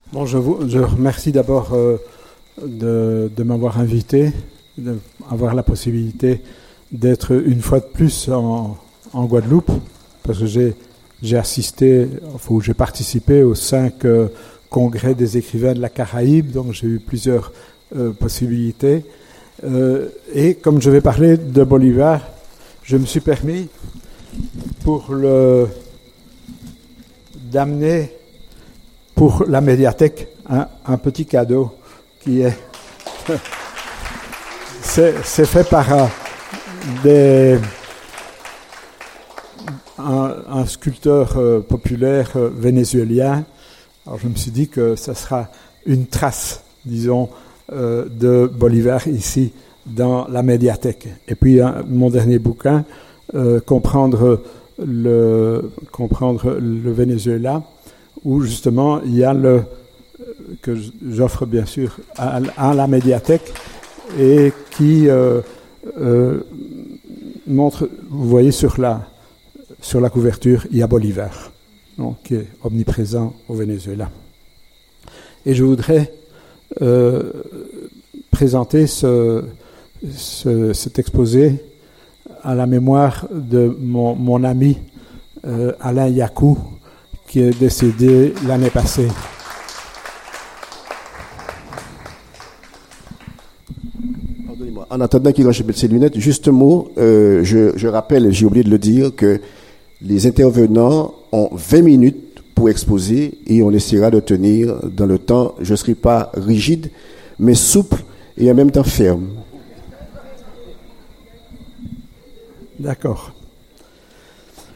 Conférence Caraïbe
le 5 octobre 2017 au Fort Fleur d'Epée (Gosier, Guadeloupe) à l'occasion de la célébration des 20 ans de Laméca.